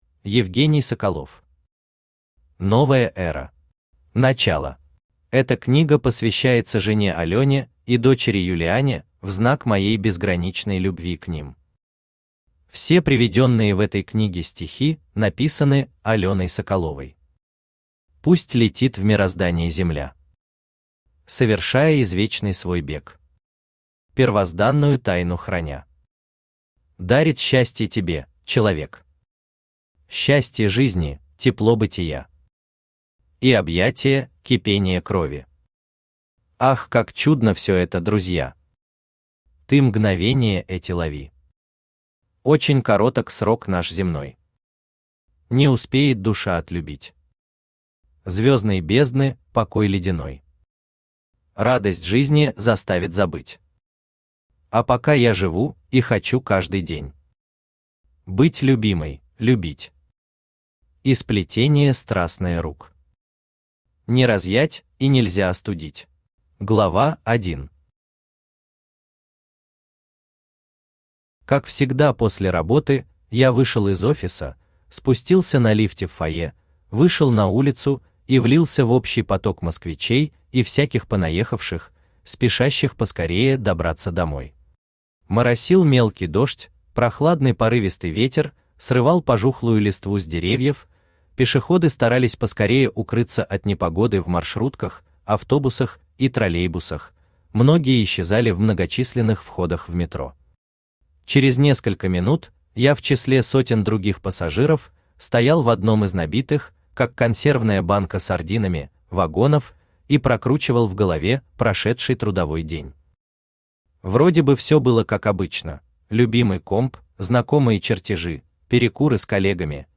Начало Автор Евгений Владимирович Соколов Читает аудиокнигу Авточтец ЛитРес.